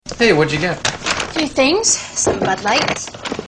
autotune